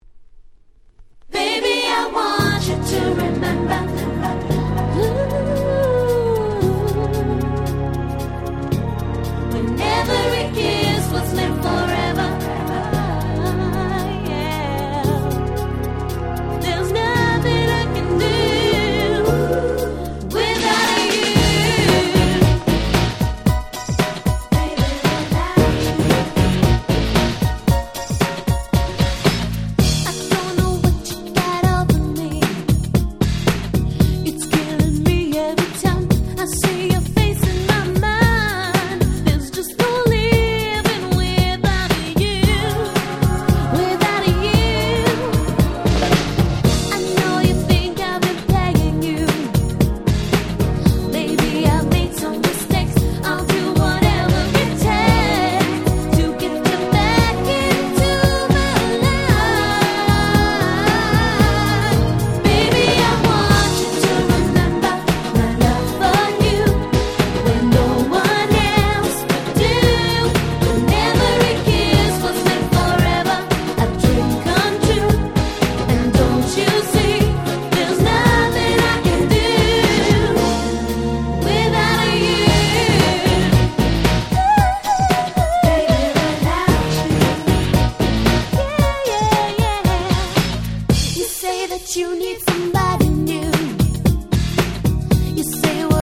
00' Nice EU R&B !!
キャッチー系